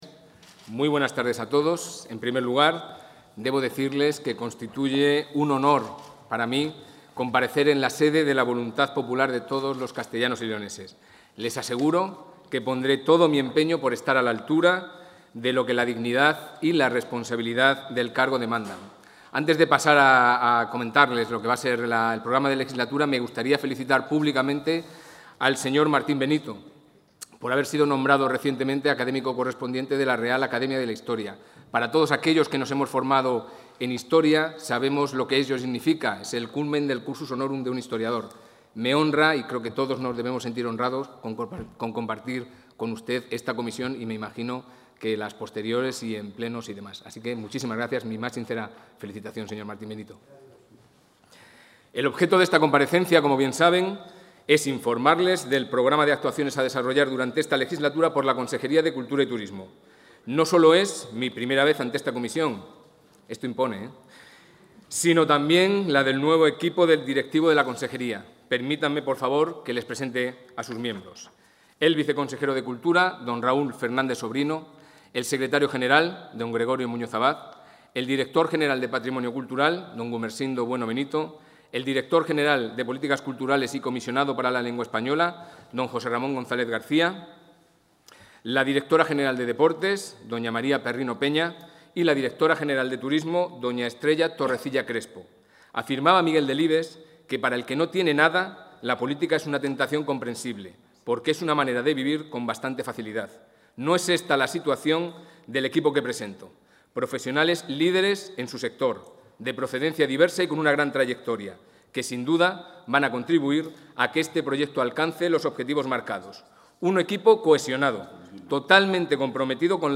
El consejero de Cultura y Turismo, Javier Ortega Álvarez, ha comparecido hoy en la Comisión de Cultura y Turismo de las Cortes de...
Audio consejero.